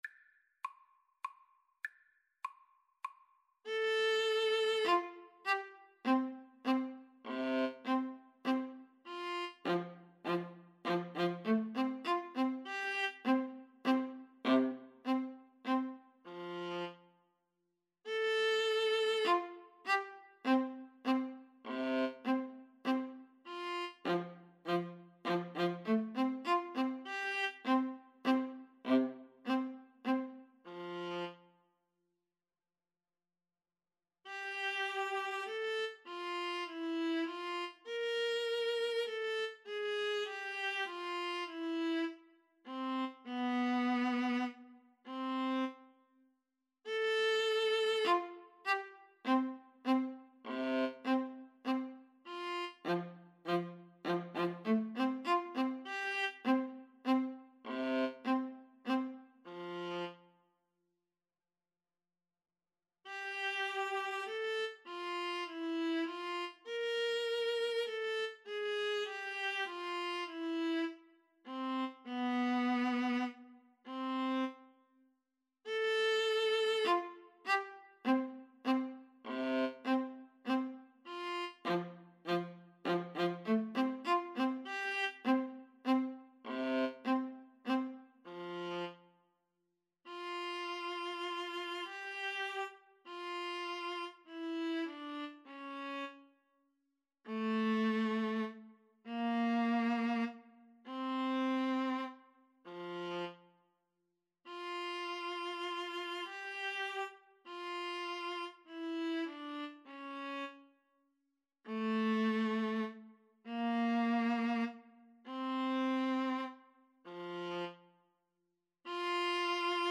Allegretto - Menuetto
Classical (View more Classical Clarinet-Viola Duet Music)